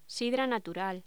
Locución: Sidra natural
voz